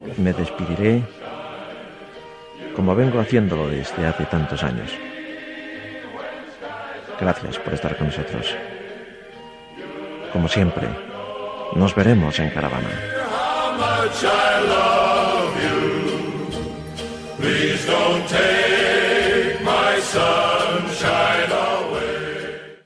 Comiat del programa i tema musical.
Musical